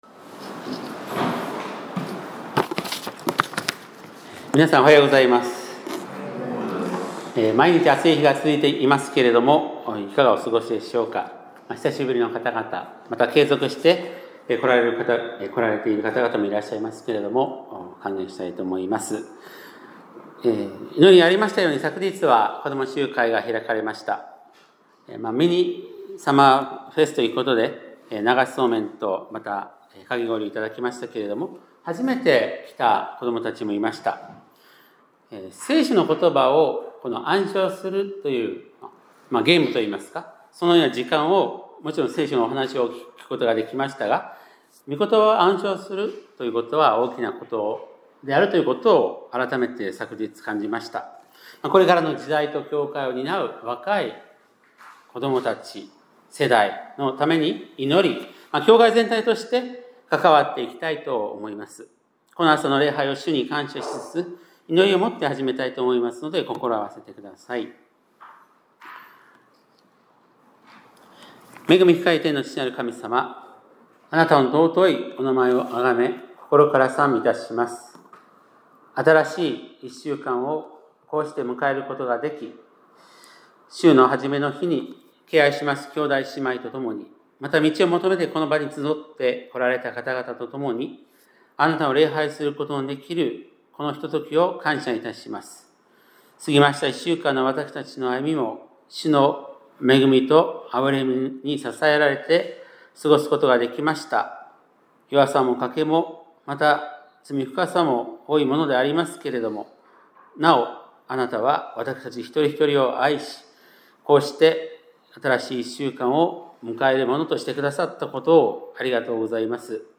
2025年7月13日（日）礼拝メッセージ - 香川県高松市のキリスト教会
2025年7月13日（日）礼拝メッセージ